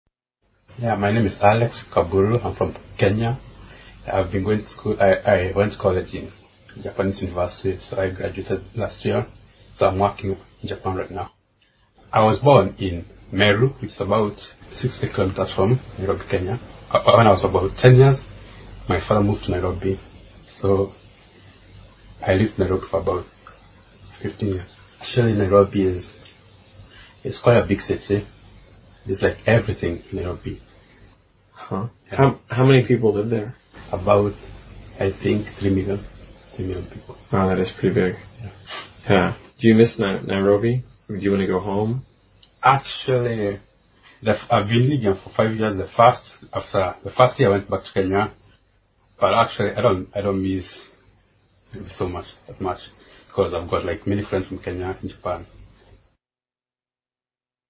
英语访谈对话 110 Wales 听力文件下载—在线英语听力室